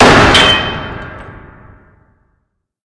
Двойной удар по трубе.